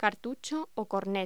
Locución: Cartucho o cornet
voz